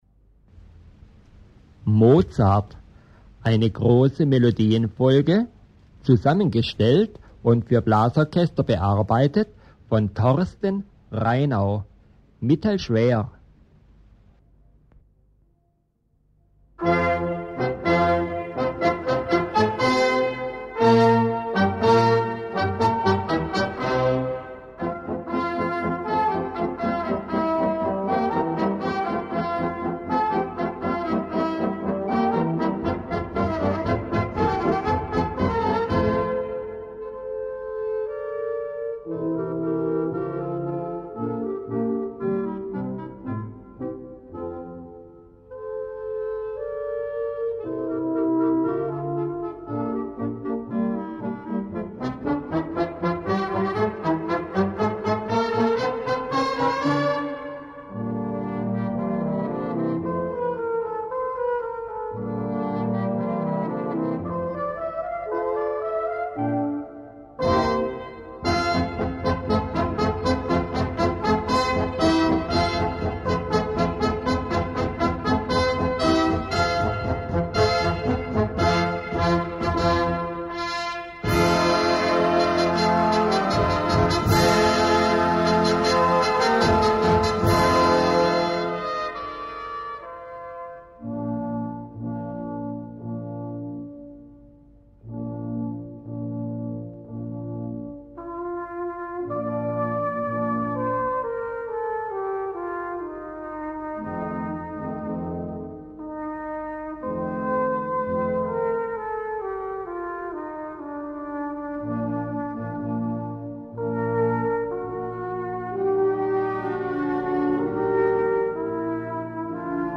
Gattung: Melodienfolge
A4 Besetzung: Blasorchester PDF